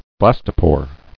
[blas·to·pore]